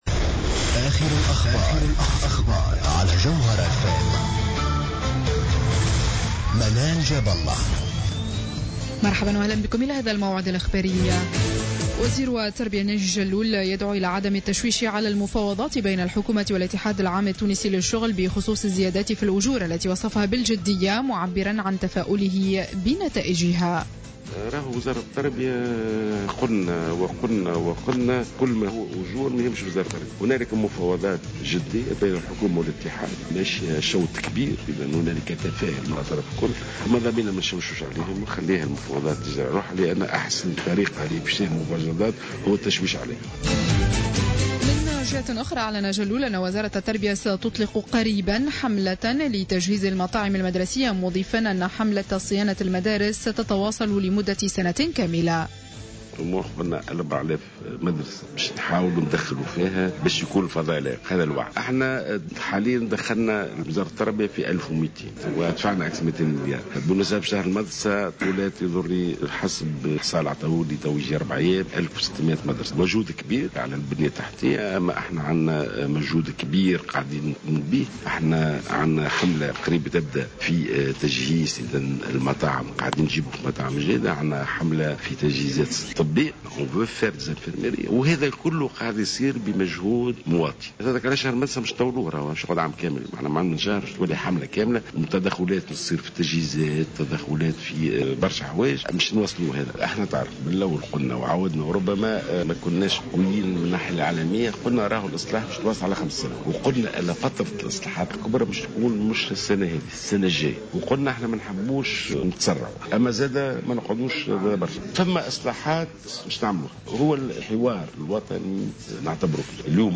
نشرة أخبار منتصف الليل ليوم الإثنين 31 أوت 2015